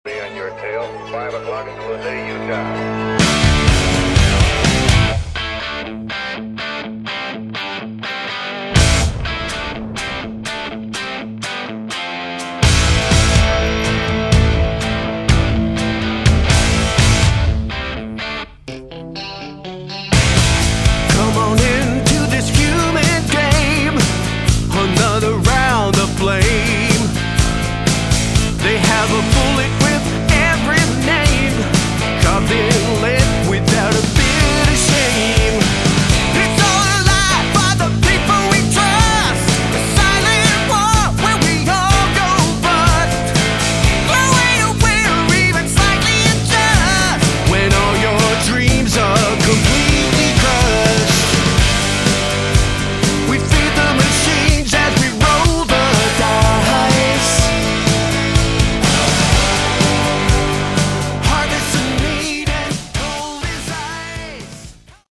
Category: Melodic Rock
guitars, bass, keyboards, backing vocals
lead vocals